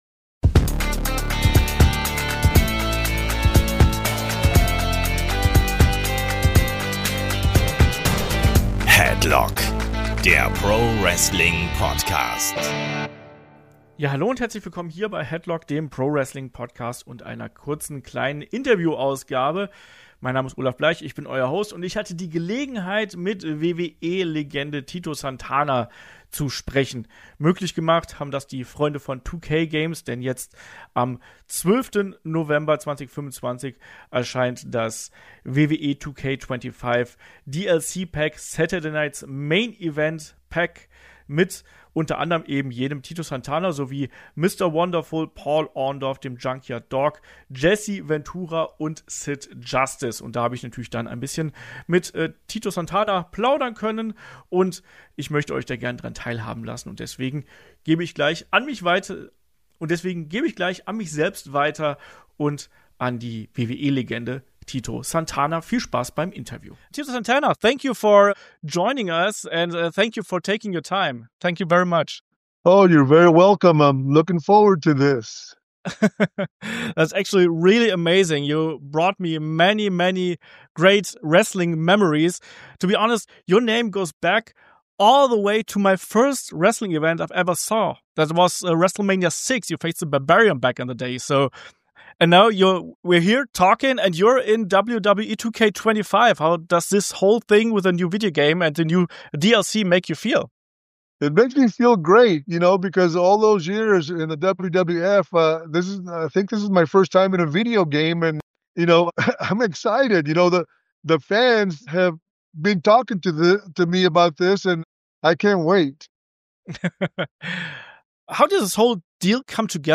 Mit dabei: Der ehemalige Intercontinental-Champion Tito Santana als spielbarer Charakter. In Zusammenarbeit mit 2K Games präsentieren wir euch ein Kurz-Interview mit der WWE-Legende, in dem Santana über einige Höhepunkte seiner Karriere spricht.